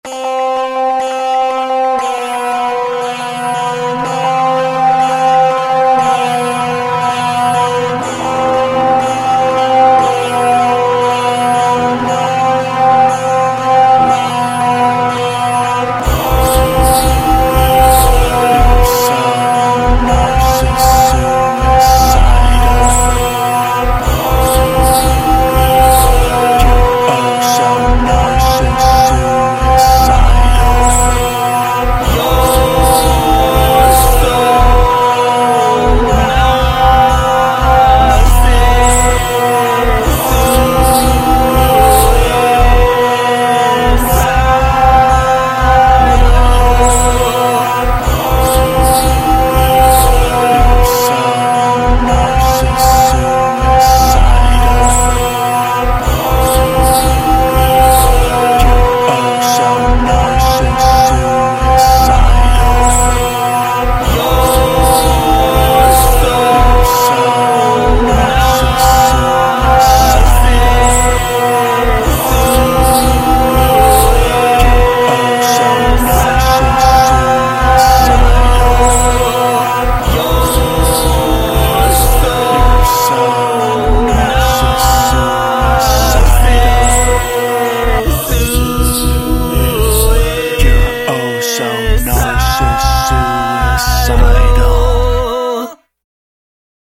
These songs were done using the "Track Swapping" method.